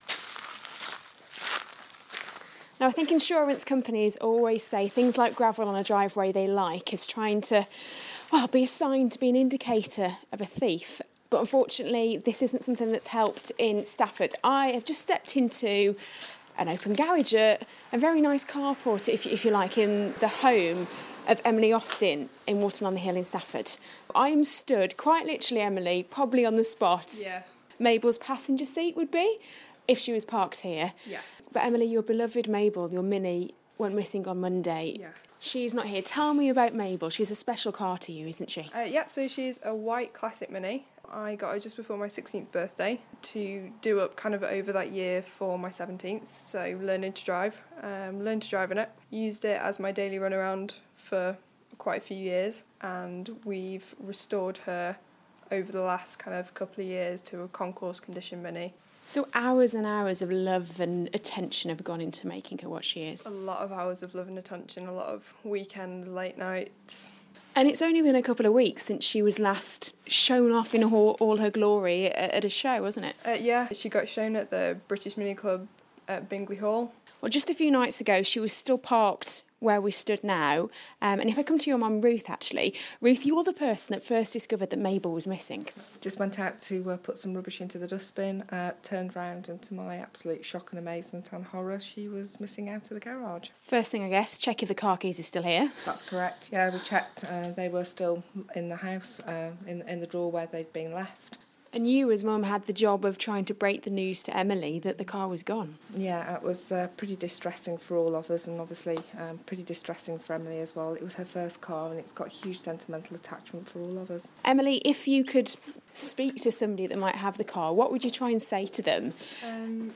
(Broadcast on BBC Radio Stoke, February 2016)